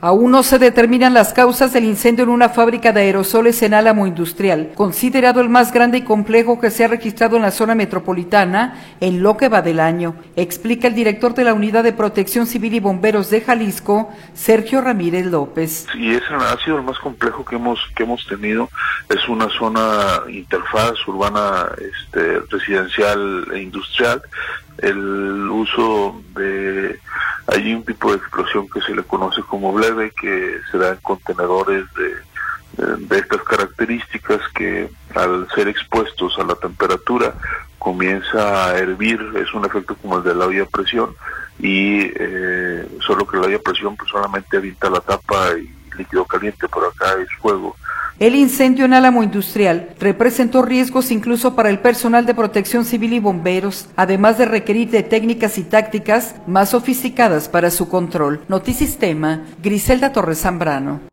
Aún no se determinan las causas del incendio en una fábrica de aerosoles en Alamo Industrial, considerado el más grande y complejo que se ha registrado en la zona metropolitana en lo que va del año, explica el director de la Unidad de Protección Civil y Bomberos de Jalisco, Sergio Ramírez López.